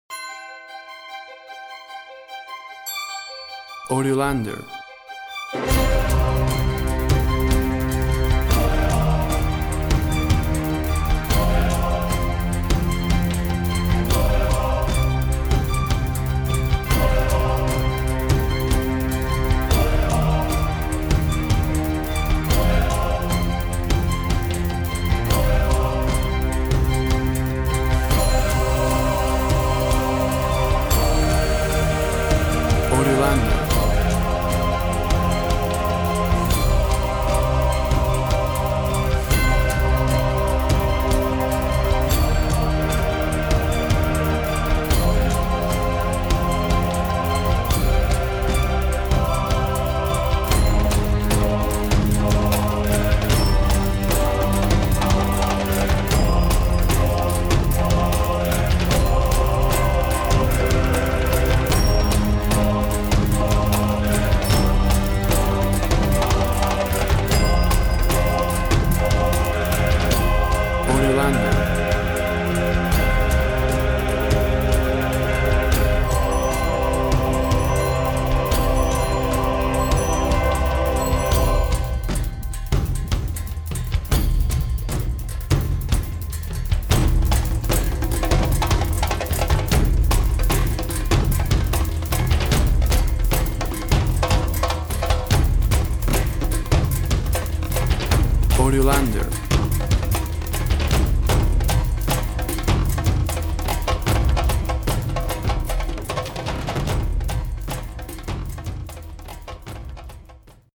Orchestra electronic intensity.
Tempo (BPM) 150